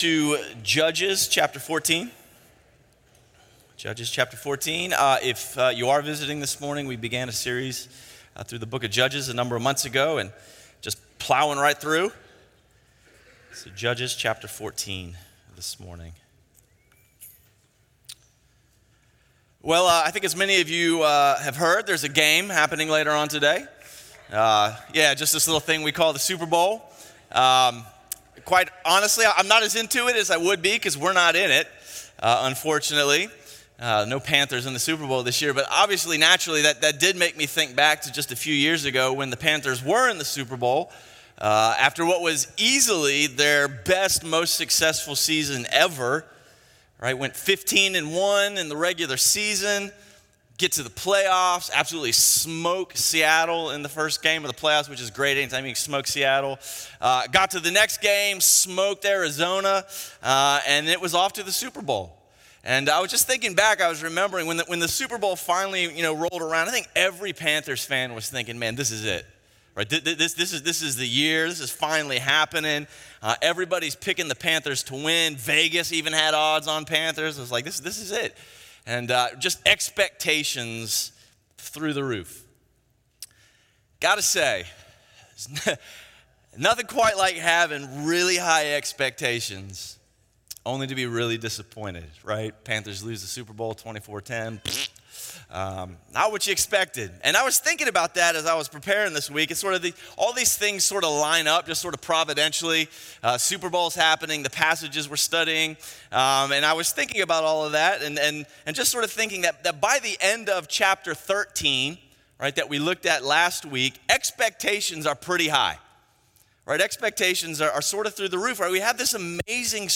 A message from the series "Faith Works."